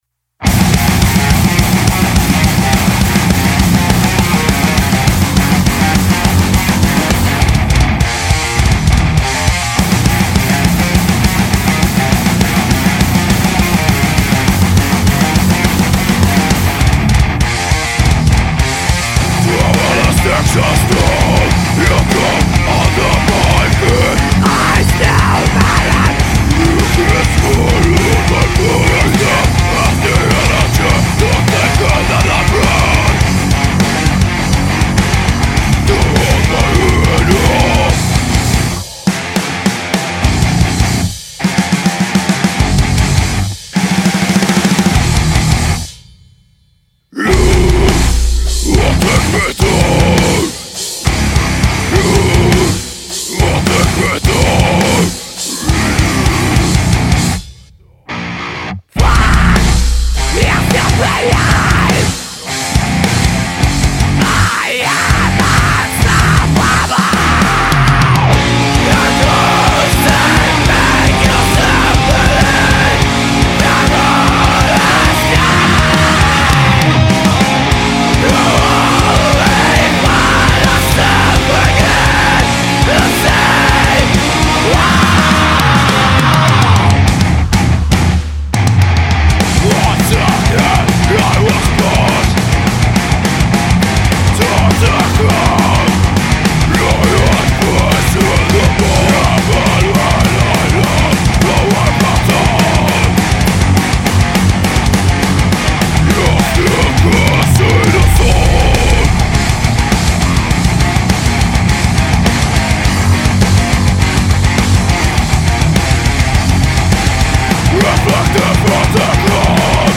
Žánr: Metal/HC
hardcore-deathcore crossover band